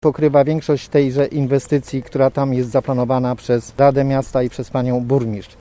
To znaczące wsparcie w przypadku tej inwestycji, mówi poseł Marek Kwitek: